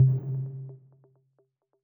pause-back-hover.wav